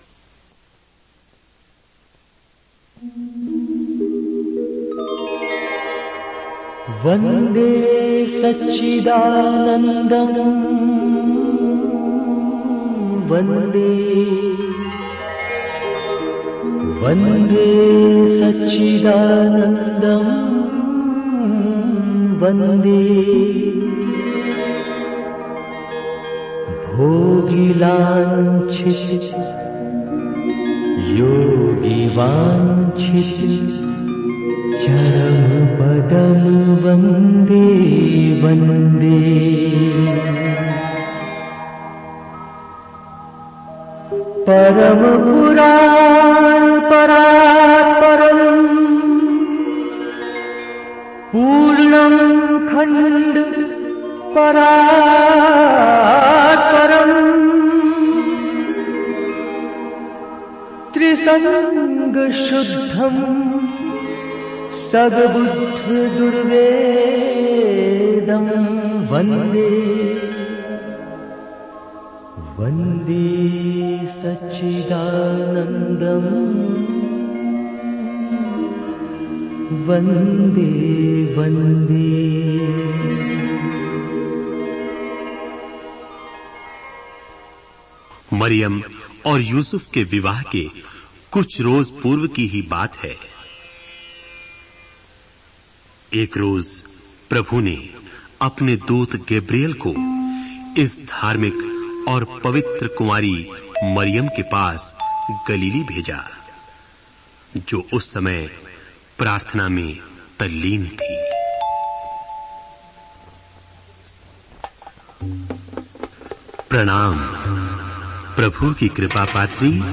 Directory Listing of mp3files/Hindi/Bible Dramas/Dramas/ (Hindi Archive)